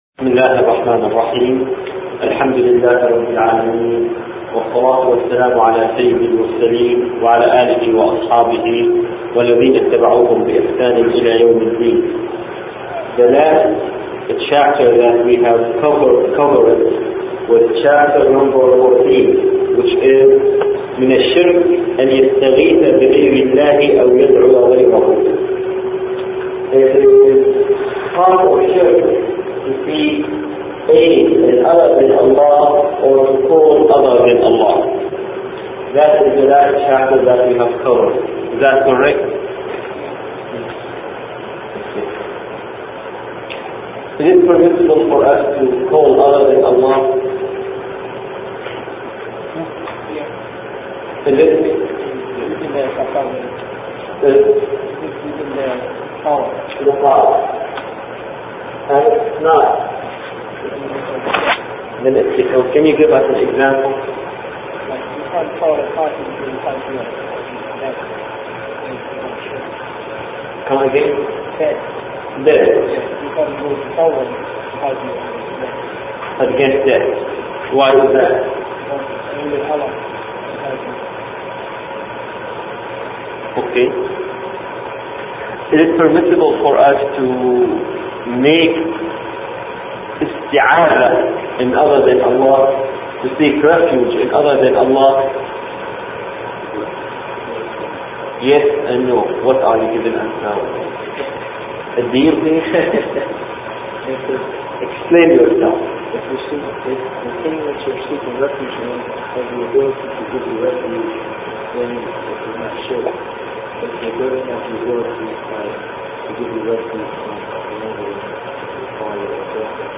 المحاضر